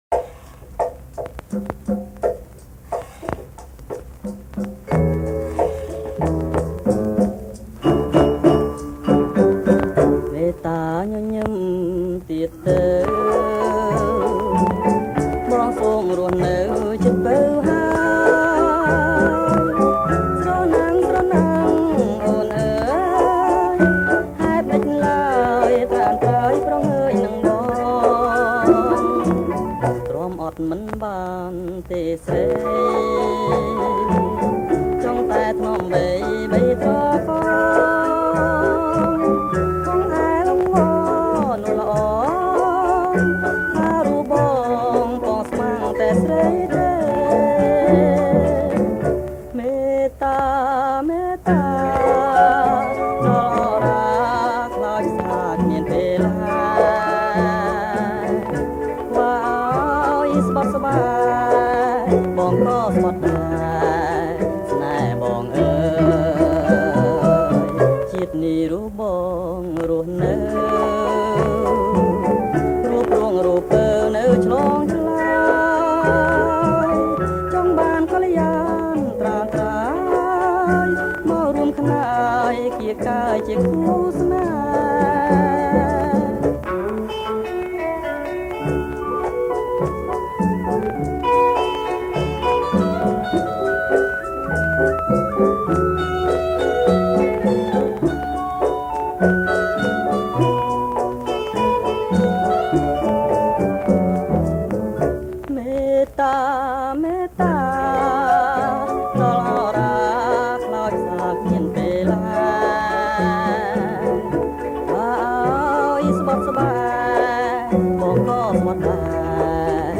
ប្រគំជាចង្វាក់ Rumba Surf